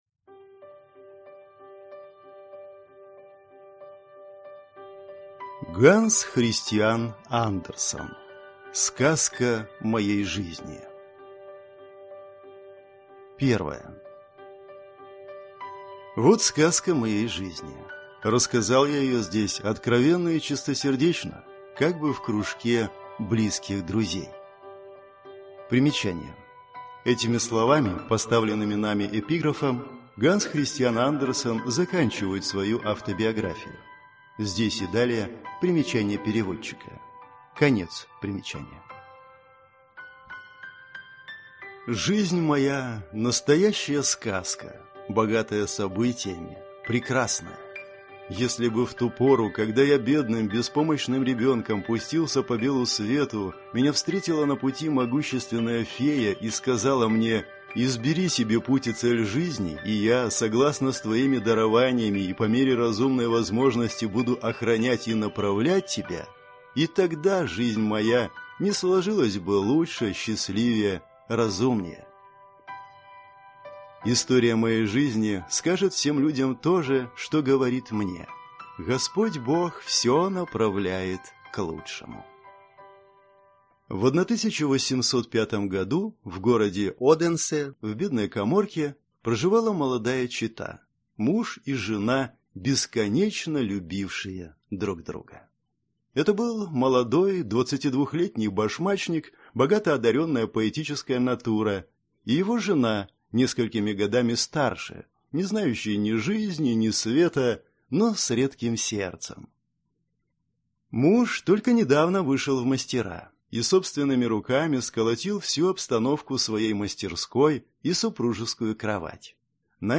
Аудиокнига Сказка моей жизни | Библиотека аудиокниг